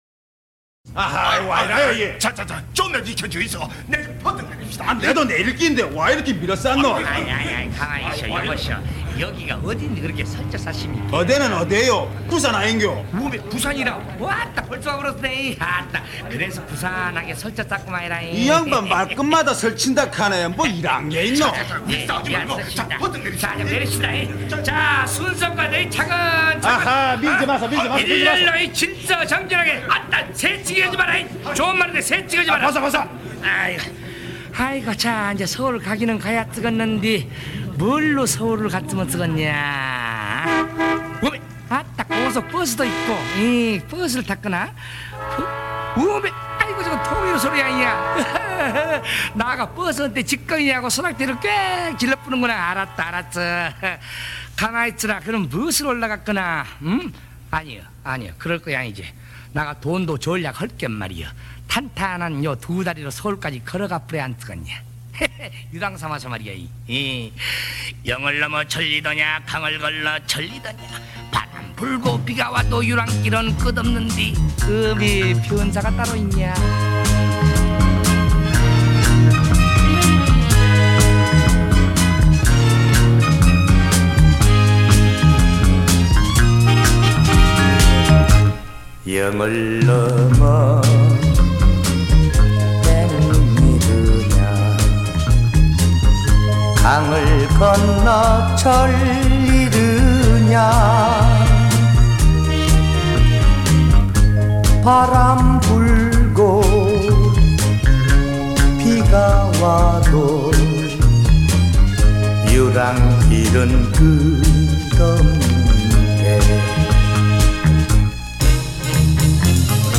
대사